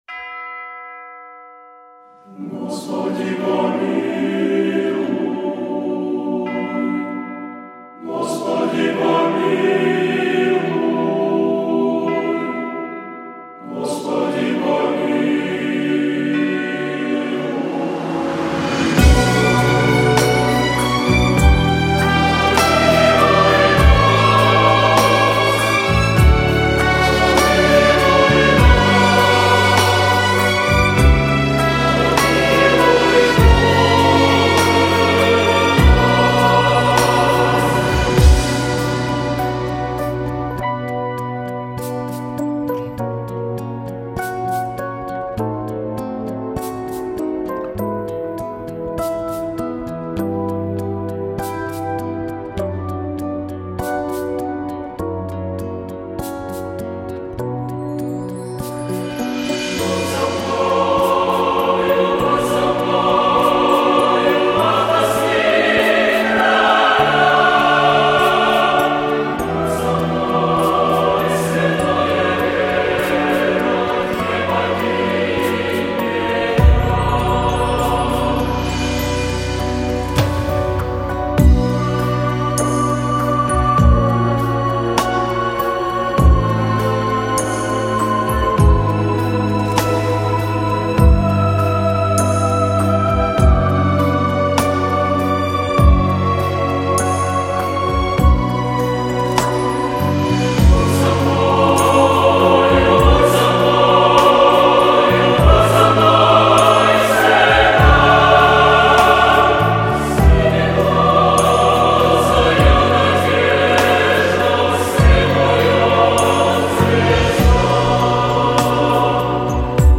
Вроде исполняет какой-то церковный хор. но это не точно